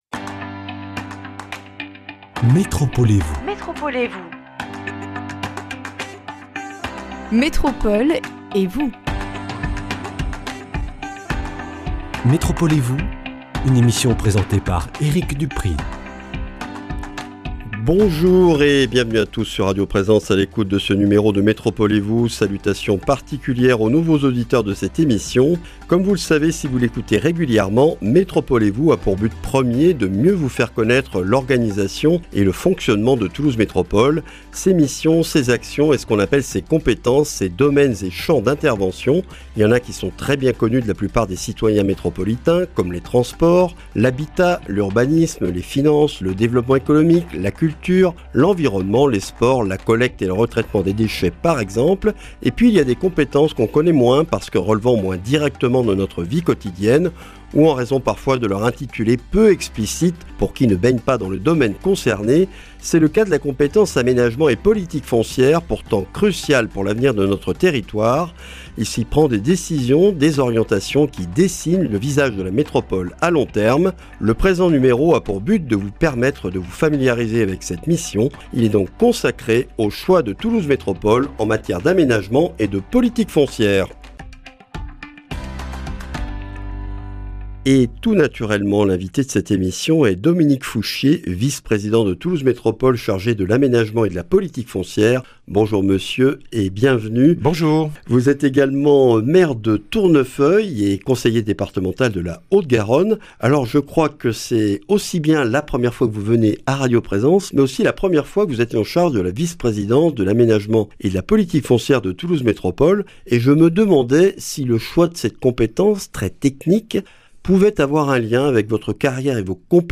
Rediffusion : Dominique Fouchier, maire de Tournefeuille, conseiller départemental de la Haute-Garonne et vice-président de Toulouse Métropole chargé de l’Aménagement de la Politique foncière, est l’invité de ce numéro. L’occasion de découvrir les choix et orientations de la Métropole en matière d’aménagement et de politique foncière, deux missions de 1ère importance pour l’avenir de notre territoire.